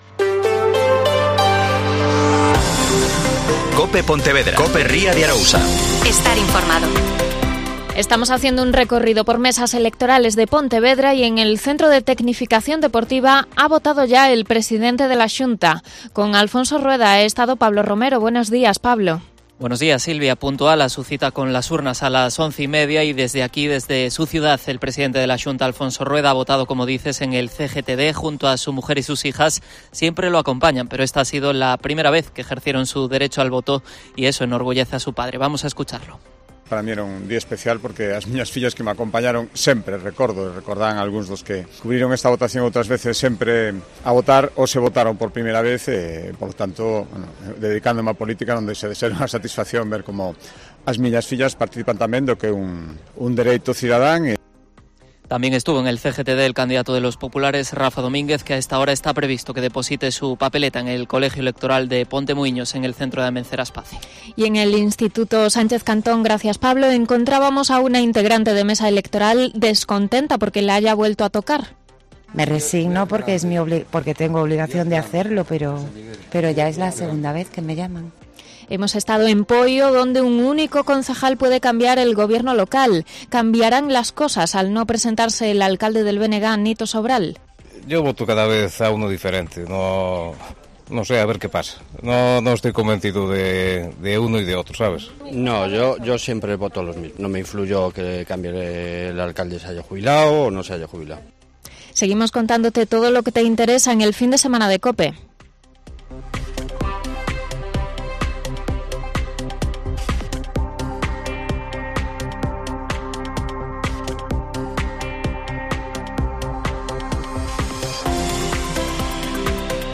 Especial Elecciones Municipales 2023 (Informativo 12,05h)